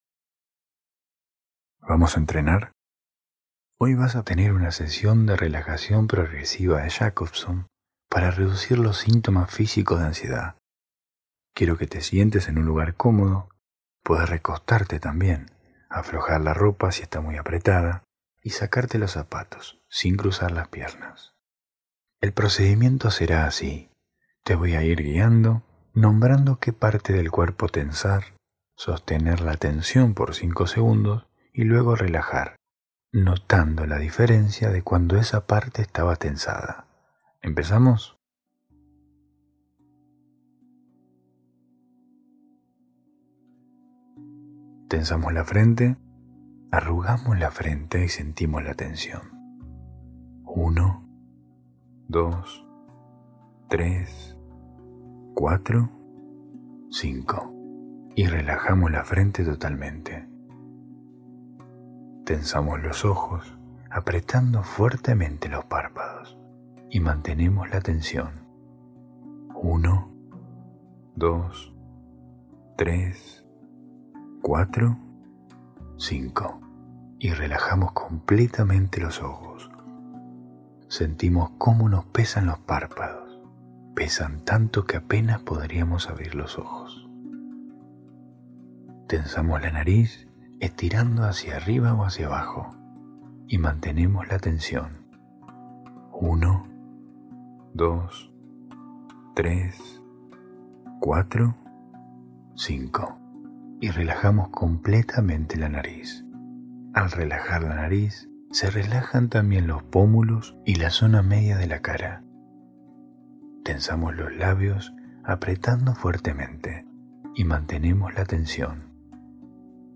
Sesión de relajación muscular progresiva para reducir los síntomas físicos de ansiedad